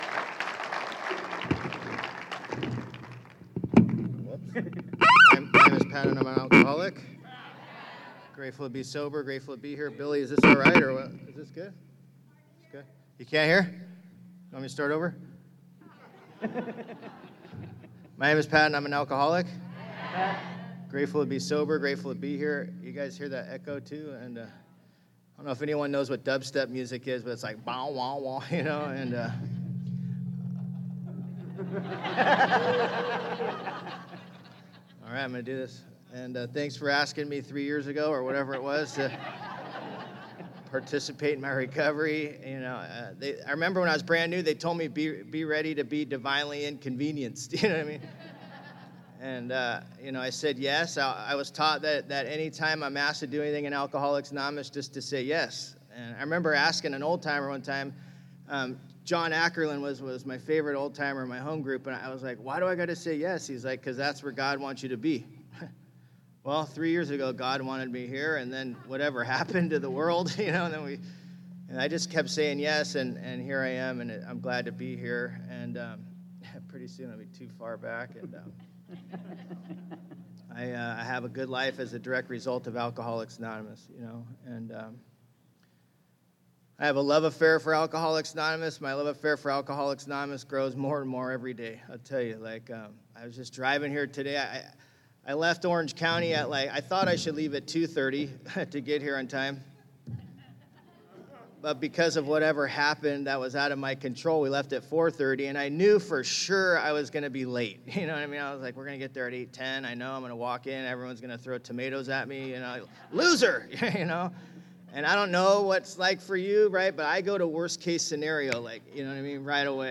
35th Annual Ventura County Serenity By The Sea